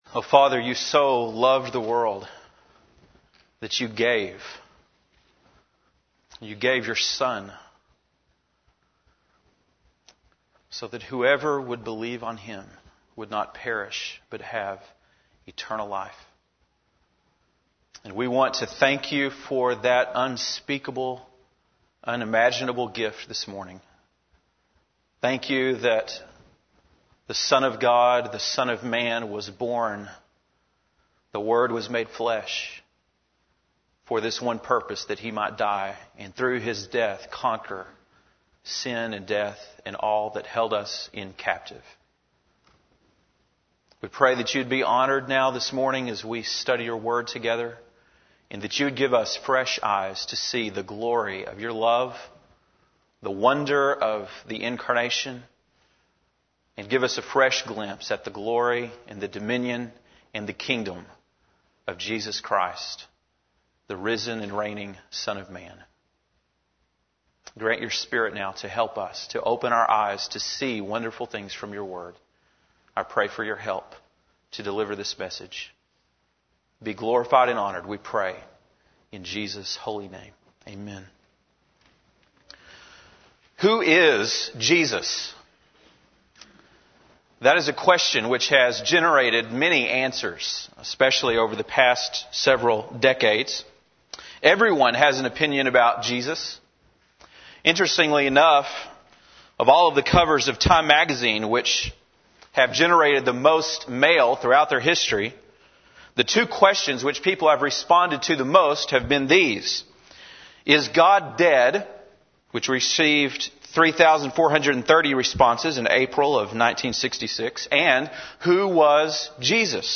December 11, 2005 (Sunday Morning)